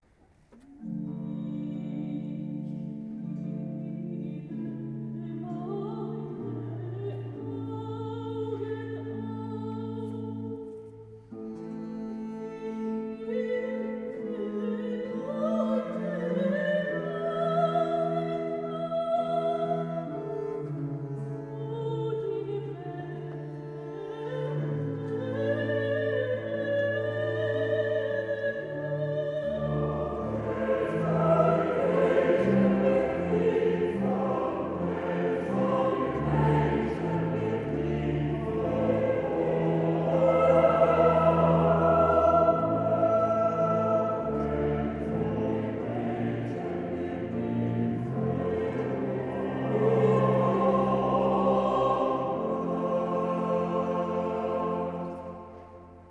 von Heinrich Schütz | Mit Kantorei Berner Münster
Sopranistin